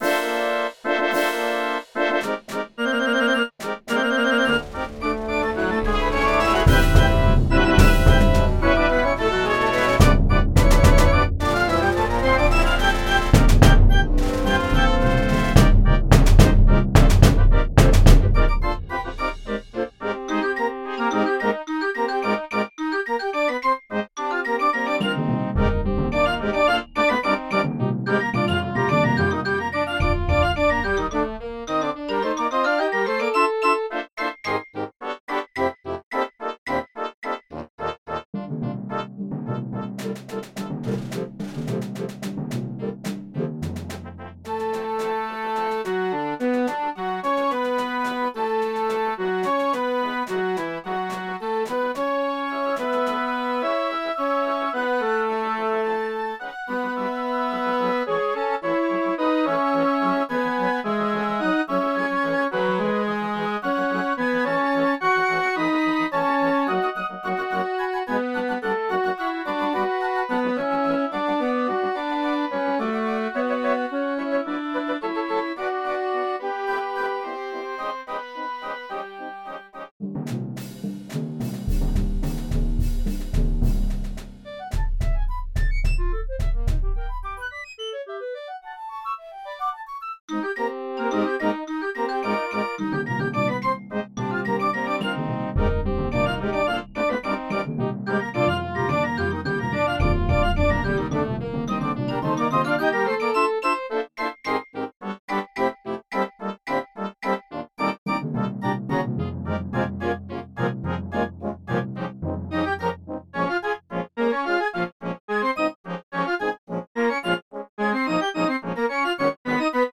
Genre: Band
Sprightly and full of life from the start
Timpani (4 Drums)
Percussion 2 (crash cymbals, suspended cymbal, 2 triangles)
Percussion 3 (xylophone, bells)